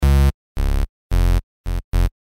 描述：平滑的吉他，鼓和平移右（或左）低音循环。 114 bpm。记录在Cubase中。
声道立体声